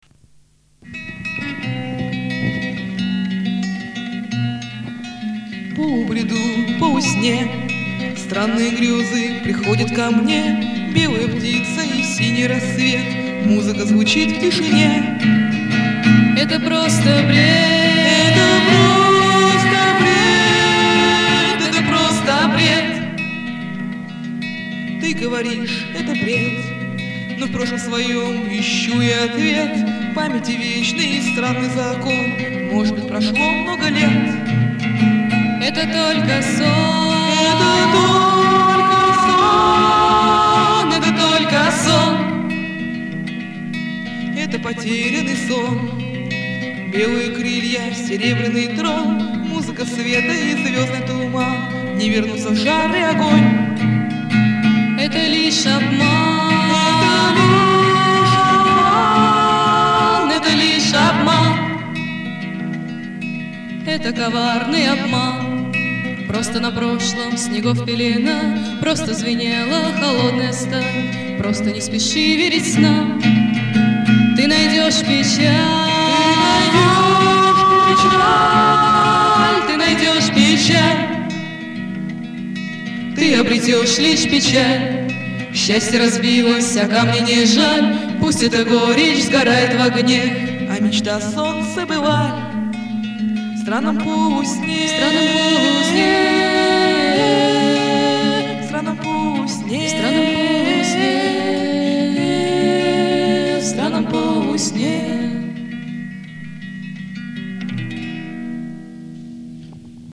второй вокал